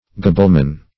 Gabelleman \Ga*belle"man\, n.